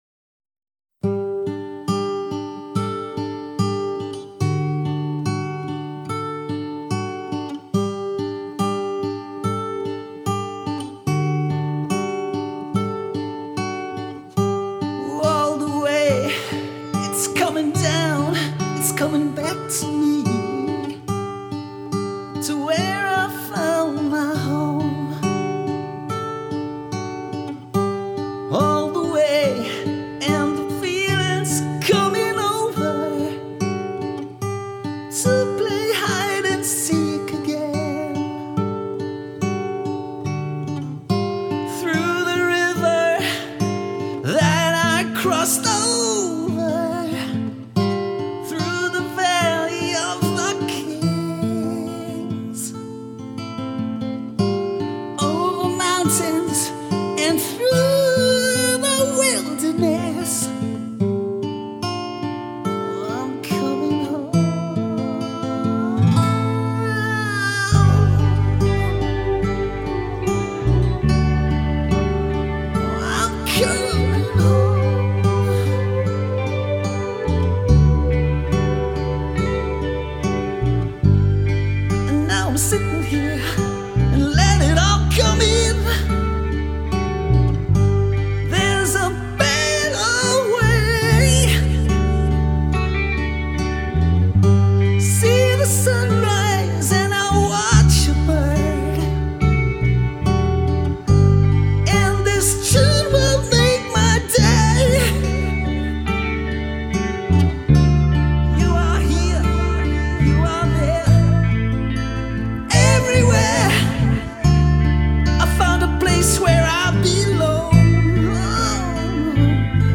I think it is awesome and when I was asked to do it in the style of Robert Plant made me smile even more.